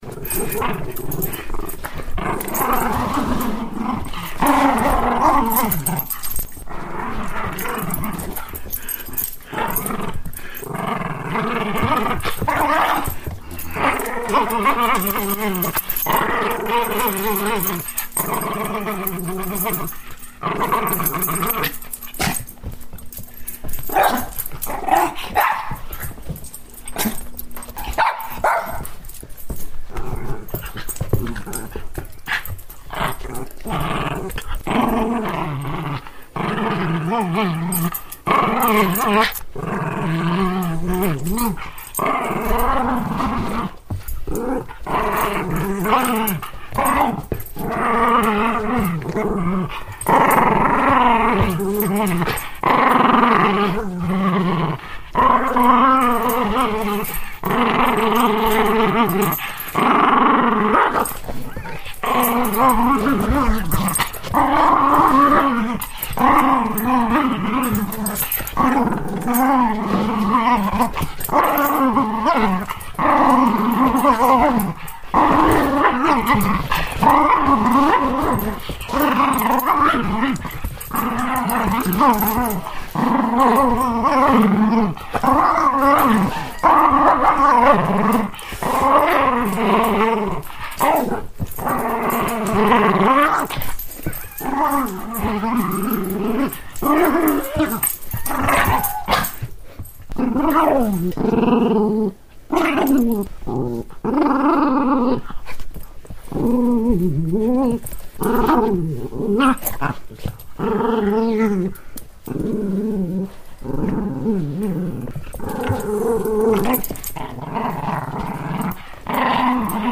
На этой странице собраны звуки собак, играющих с игрушками: лай, повизгивание, рычание и другие забавные моменты.
Звук разрыва мягкой игрушки агрессивной собакой